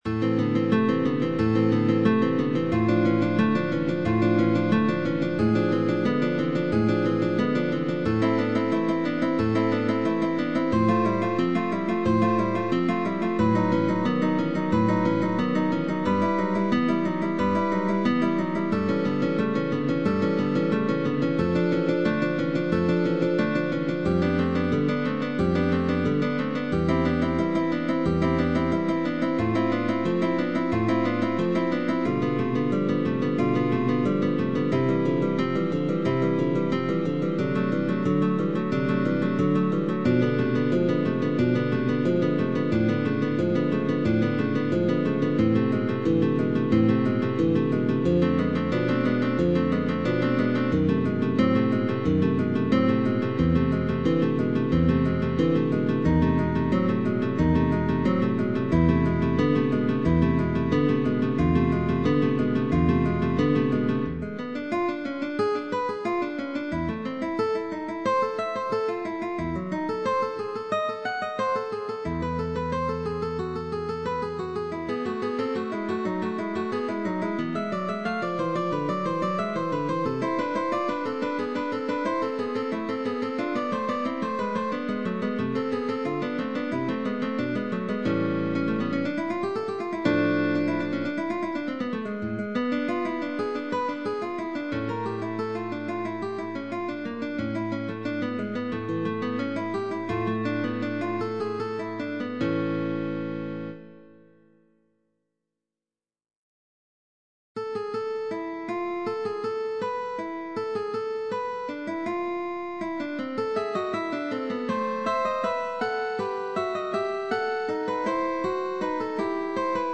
Guitar trio sheetmusic.
High register of the guitar.
GUITAR TRIO
Baroque